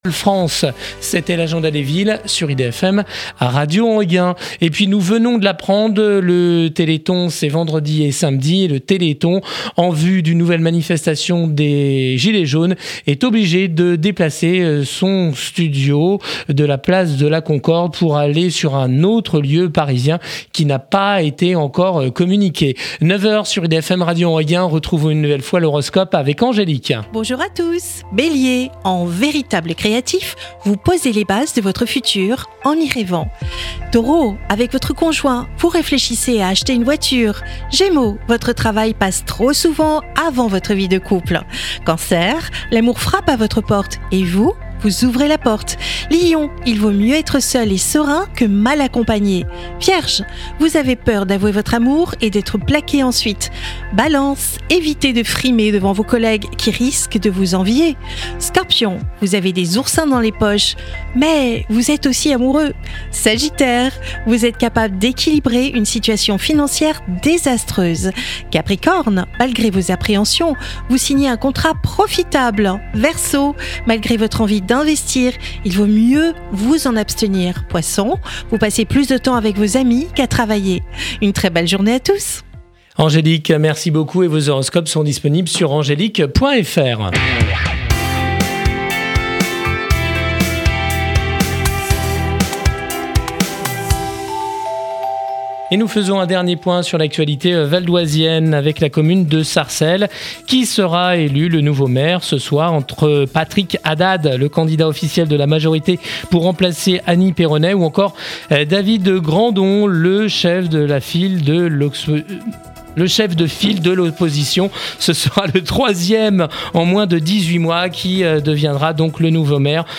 Retrouvez l'interview du Réseau Mesure et de WIKA sur Radio Enghien - émission du 05/12/2018.